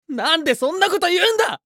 青年ボイス～ホラー系ボイス～
【なんでそんなこと言うの(激)】